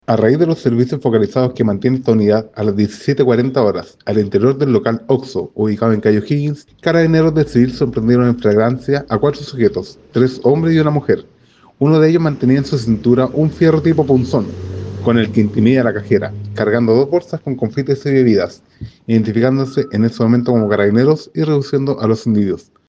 cuna-carabinero.mp3